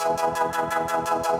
Index of /musicradar/shimmer-and-sparkle-samples/170bpm
SaS_MovingPad02_170-E.wav